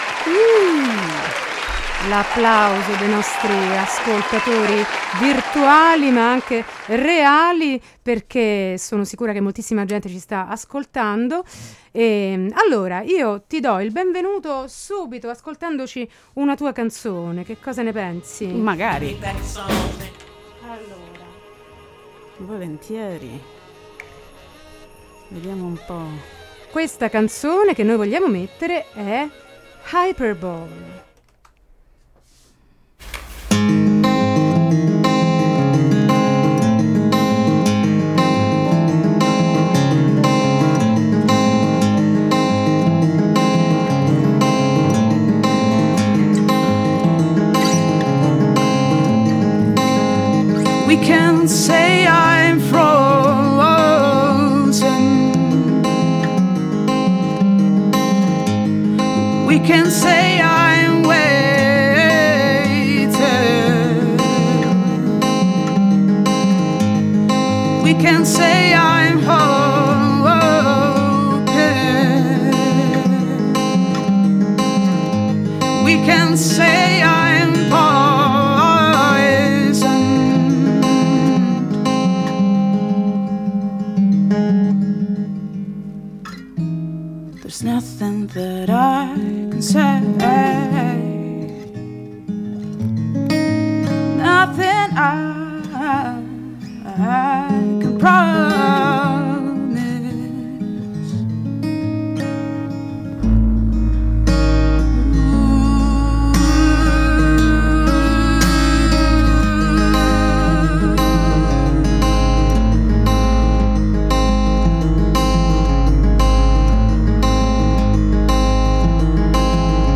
Intervista e Minilive